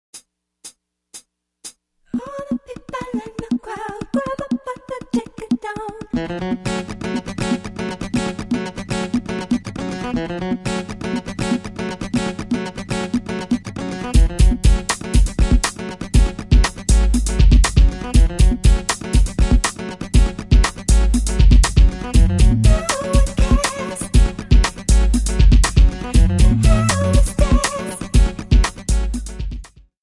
Backing track files: Duets (309)